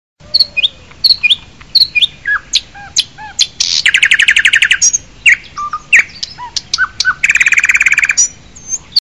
알림음(효과음) + 벨소리
알림음 8_Bird.ogg